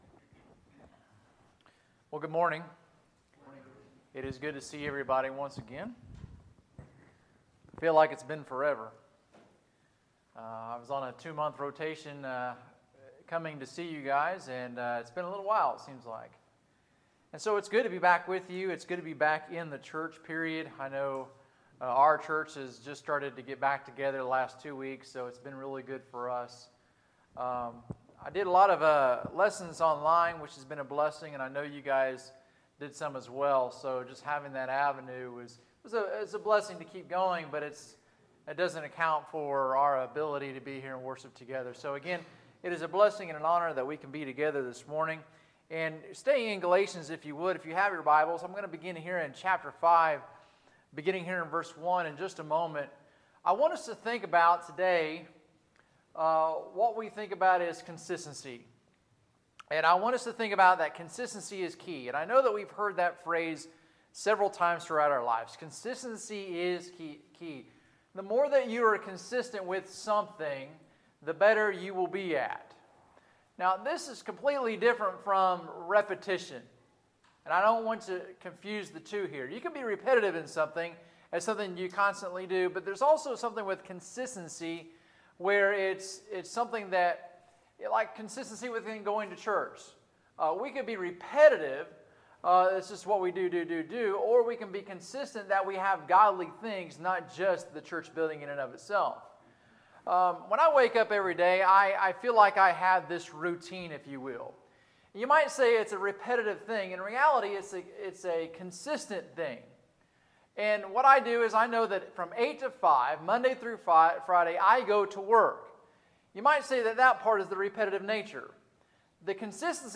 Sermons, June 14, 2020